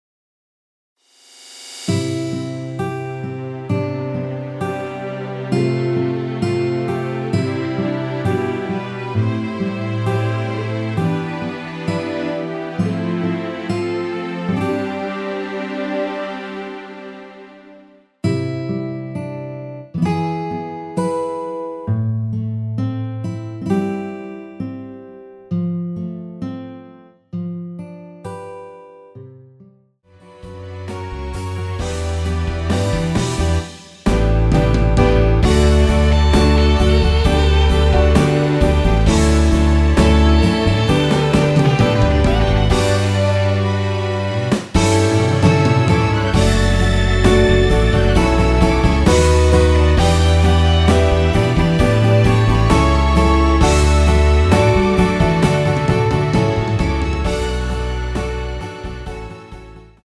원키에서(+4)올린 MR입니다.
앞부분30초, 뒷부분30초씩 편집해서 올려 드리고 있습니다.
중간에 음이 끈어지고 다시 나오는 이유는